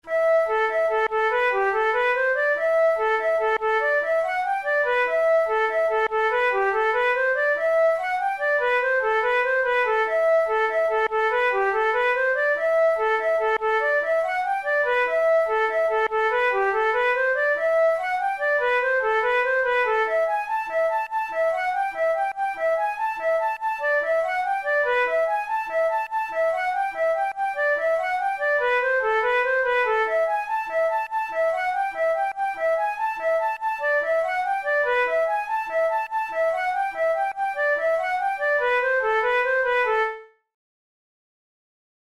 InstrumentationFlute solo
KeyA minor
Time signature6/8
Tempo96 BPM
Jigs, Traditional/Folk
Traditional Irish jig